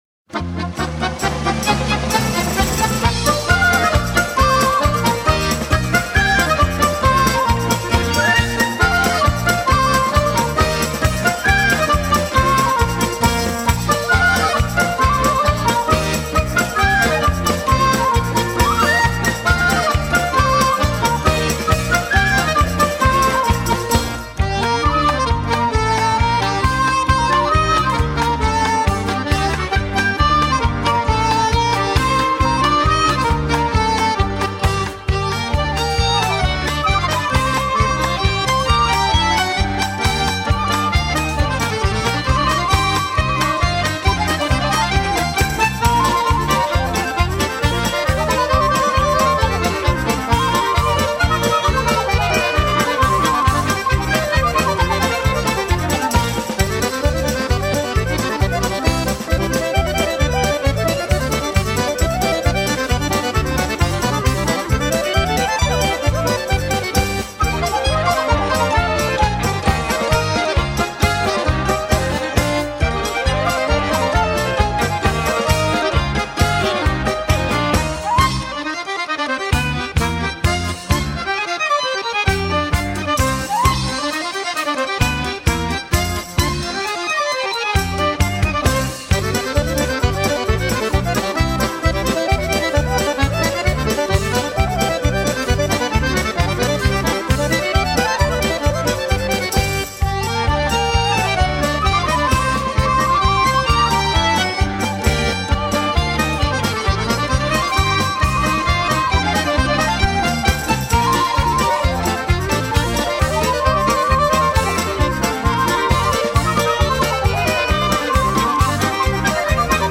Беларускія народныя танцы
Танец дынамічны, жыццярадасны. Музычны памер 2/4. Тэмп жывы.